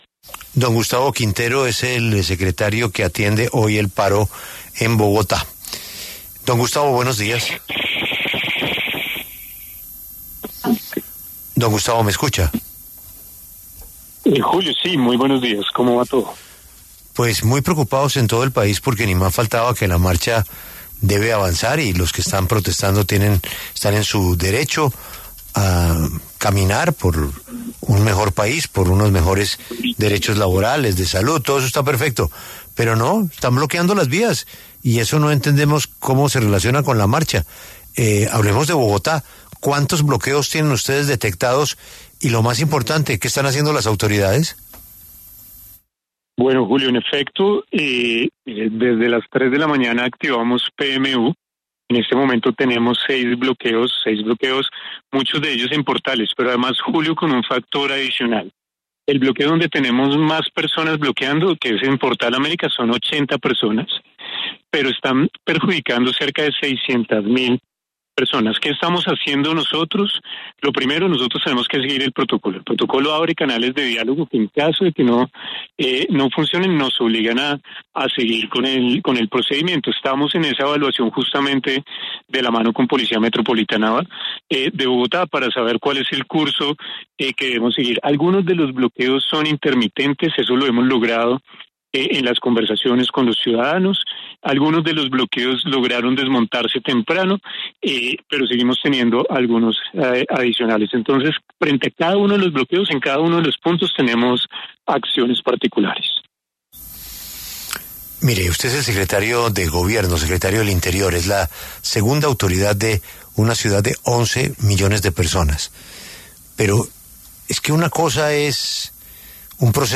Gustavo Quintero, secretario de Gobierno de Bogotá, conversó con La W a propósito de los bloqueos en la capital en medio del paro nacional.